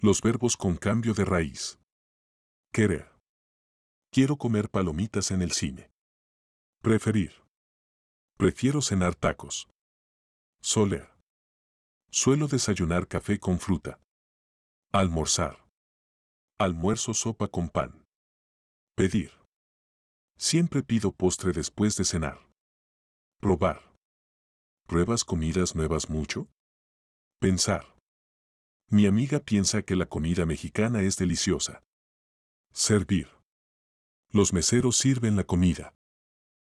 Play the audio to hear how these stem-changing verbs are pronounced.